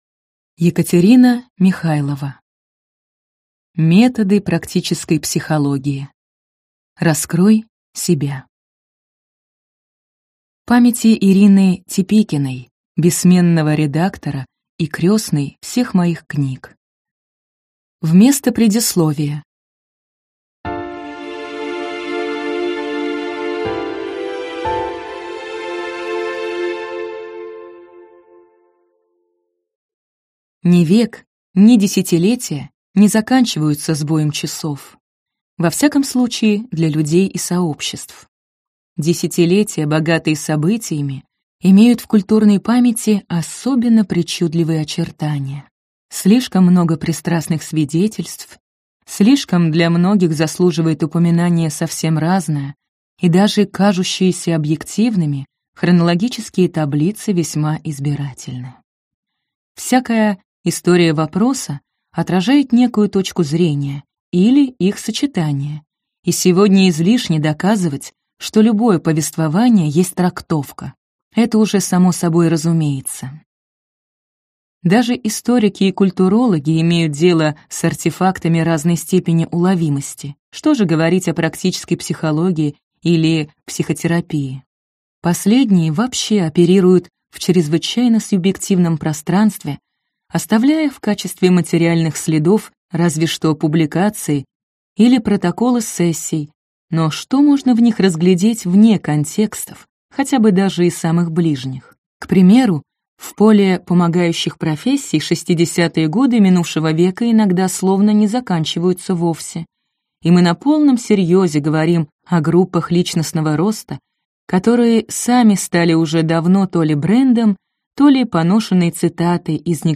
Аудиокнига Методы практической психологии. Раскрой себя | Библиотека аудиокниг